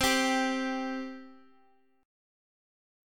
C5 chord
C-5th-C-x,x,x,x,1,3-1-down-Guitar-Standard-1.m4a